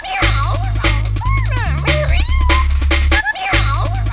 Joka jaksossa kissa kertoi naukuvalla äänellään - jota esitti edesmennyt Kenny Everett - tarinan opetuksen, jonka pikkupoika sitten tulkkasi englanniksi. #
charley-miau.wav